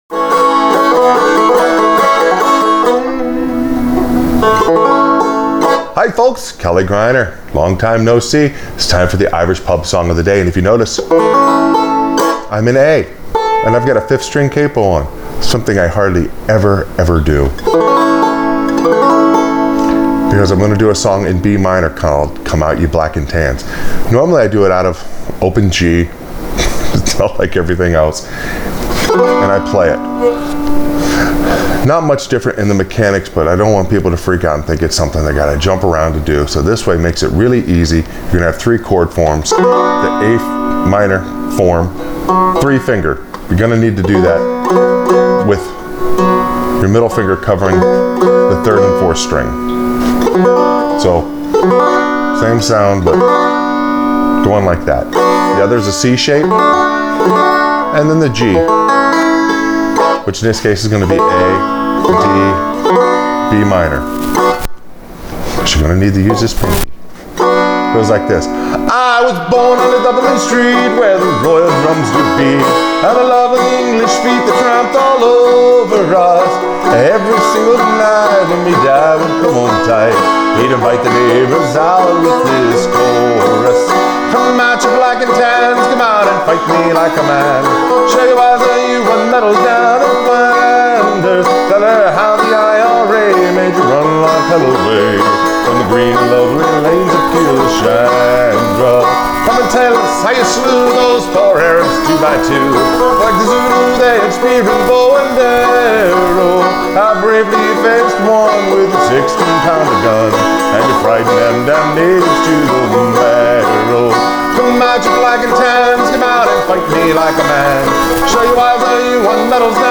Irish Pub Song Of The Day – Come Out Ye Black And Tans on Frailing Banjo